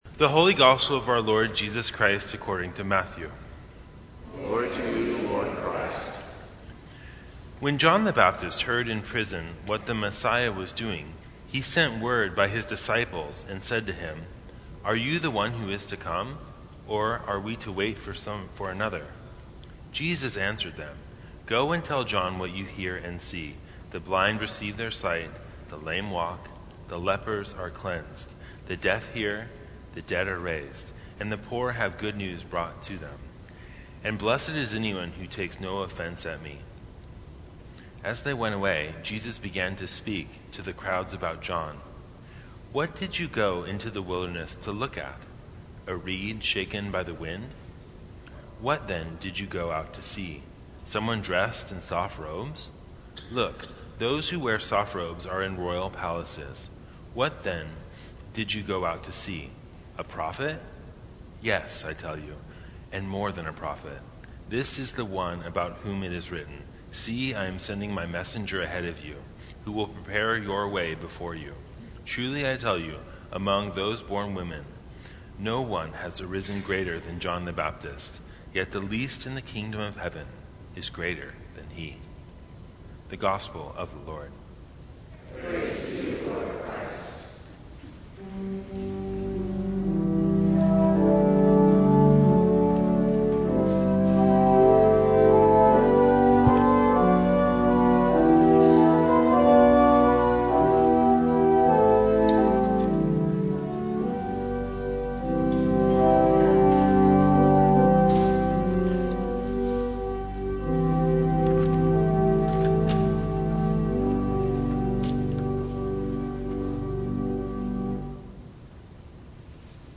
Sermons from St. Cross Episcopal Church So That Others May See Dec 18 2019 | 00:14:22 1x 00:00 / 00:14:22 Subscribe Share Apple Podcasts Spotify Overcast RSS Feed Share Link Embed